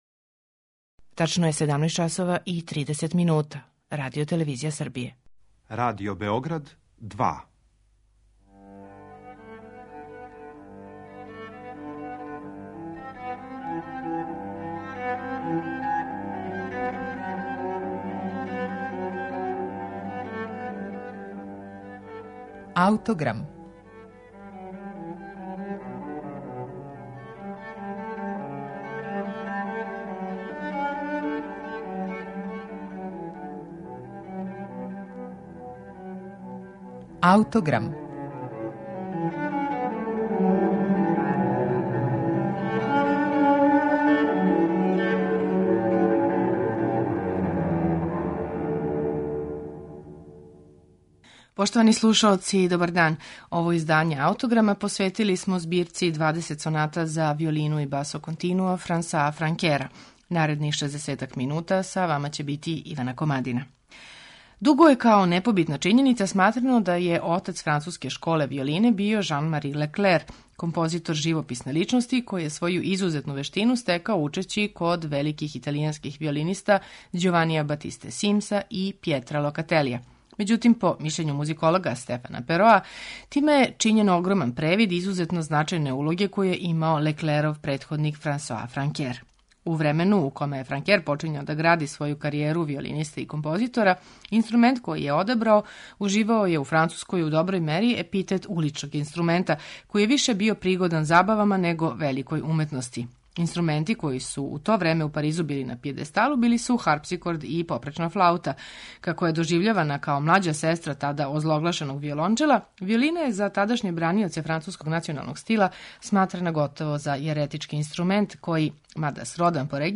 За данашњу емисију одабрали смо три сонате за виолину Франсоа Франкера
На аутентичним барокним инструментима